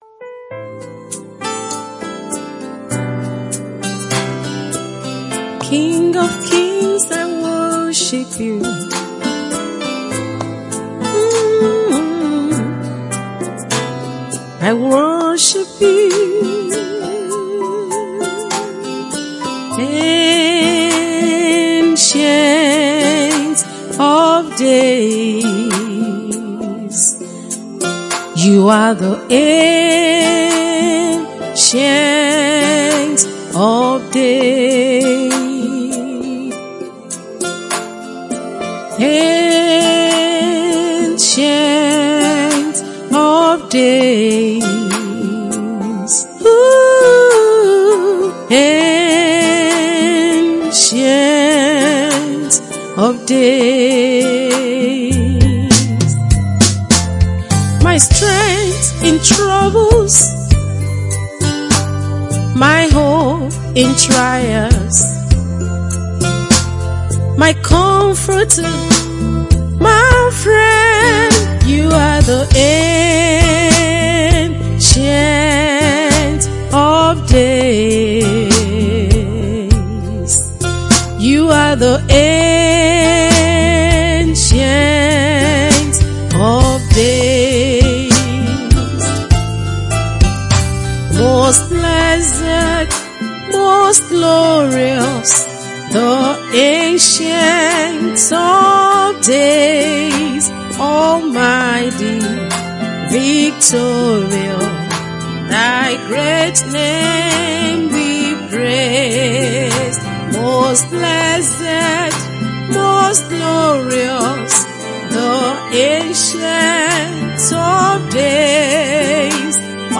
Fast rising anointed Gospel Artiste